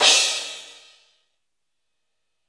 • Verby Drum Crash Sample E Key 03.wav
Royality free crash sound tuned to the E note. Loudest frequency: 4381Hz
verby-drum-crash-sample-e-key-03-pTr.wav